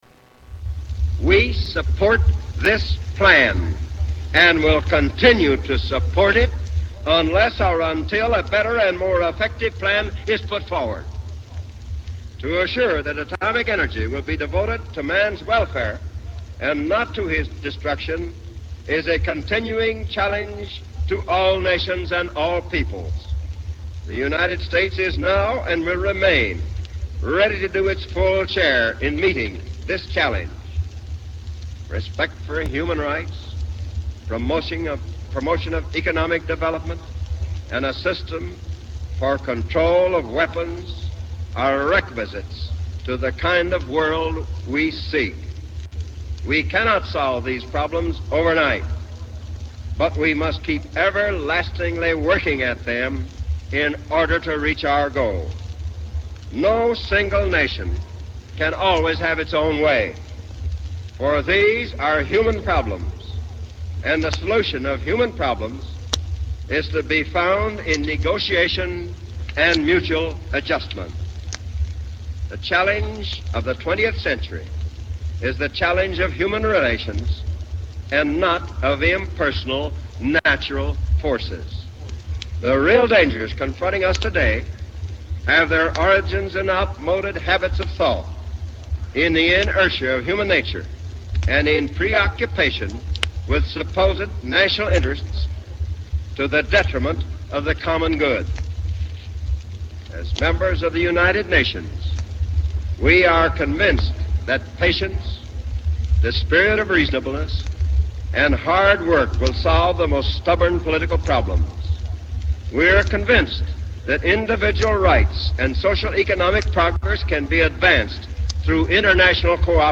October 24, 1949 - Address in New York City
Tags: History Presidents Of the U. S. President Harry S. Truman Speeches